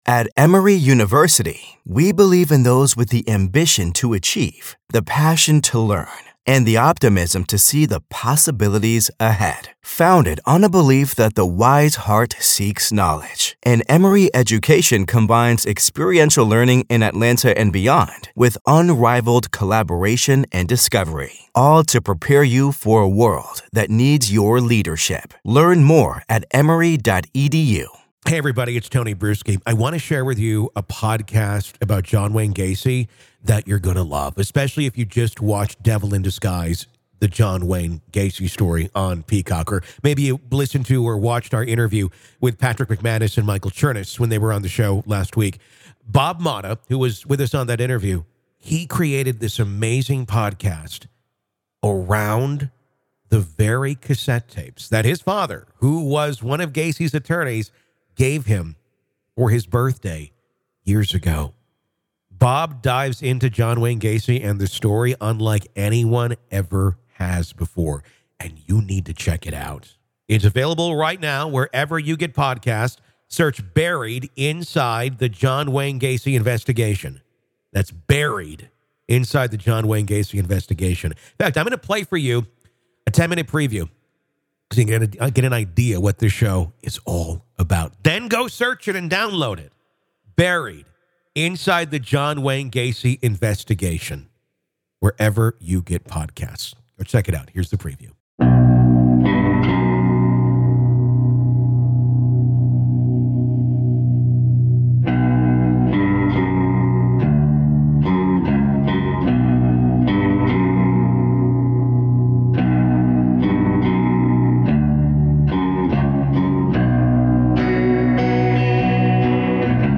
These are the original recordings and behind-the-scenes accounts, brought to life with professional remastering and deeper clarity than ever before.
Newly remastered original tapes with chilling firsthand audio
Exclusive courtroom reenactments from the historic trial